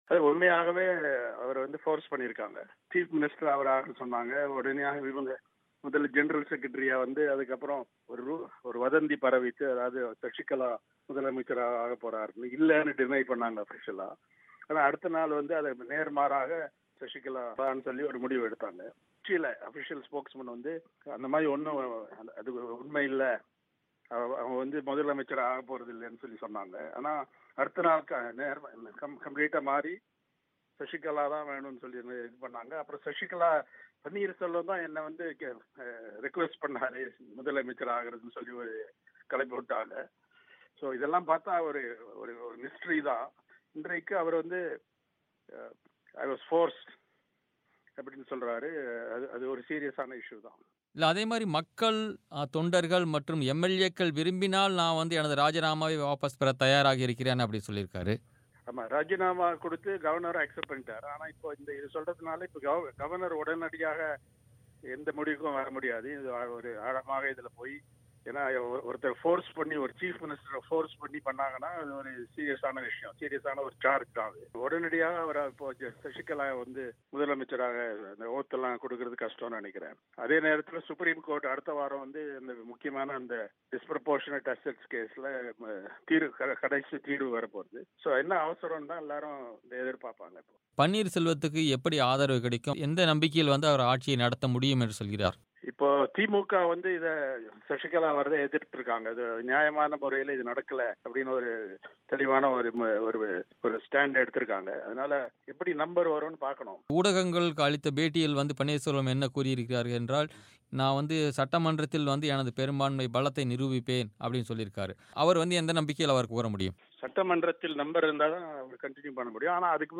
ஓ. பன்னீர் செல்வம் ஆட்சியமைத்தால் திமுக ஆதரவளிக்கும் என்று மூத்த பத்திரிகையாளர் என். ராம், பிபிசி தமிழுக்கு அளித்த பேட்டியில் தெரிவித்துள்ளார்.